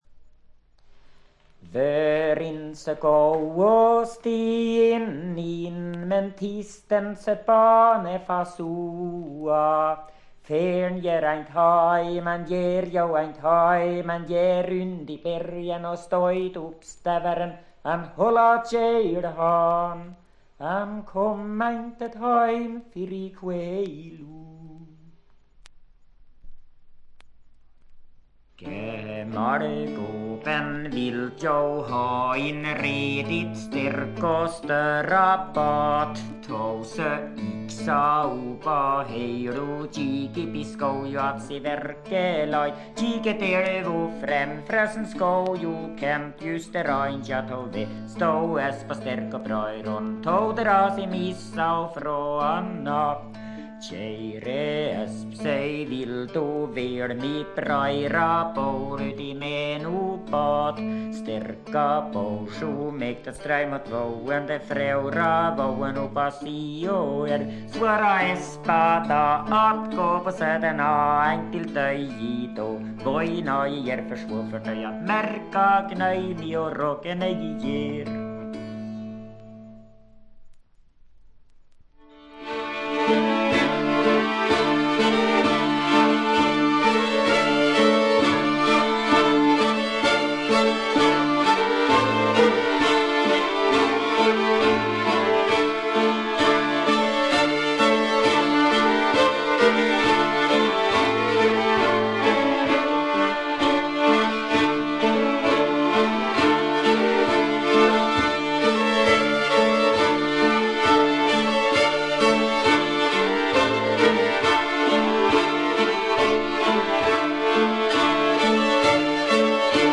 スウェーデンのトラッド・グループ
試聴曲は現品からの取り込み音源です。